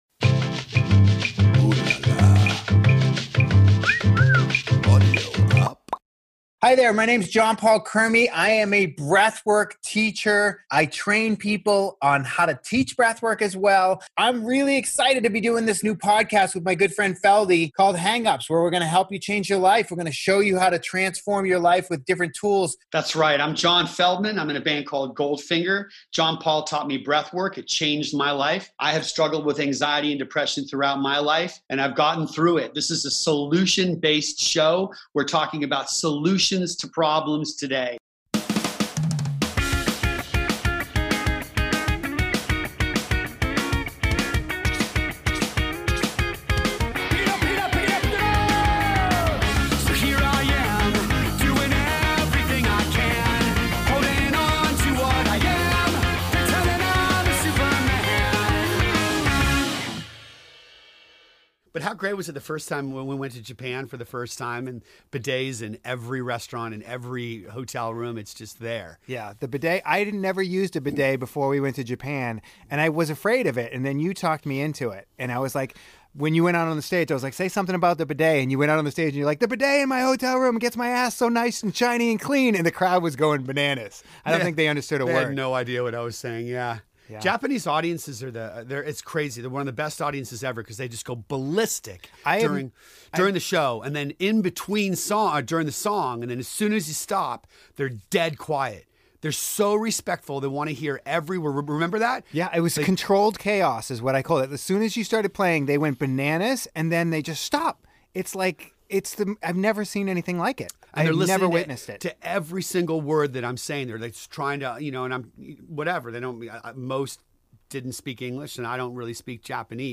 Their colorful tales range from the unusual challenges of life on tour, to subjects closer to home like marriage issues, dating, diet, exercise, parenting and addiction recovery. These candid, always raucous conversations address timely, more internalized subjects as well, that rockstars and everyday folks alike can relate to: anxiety, depression, impulse control, panic disorders and a galaxy of other modern-day concerns.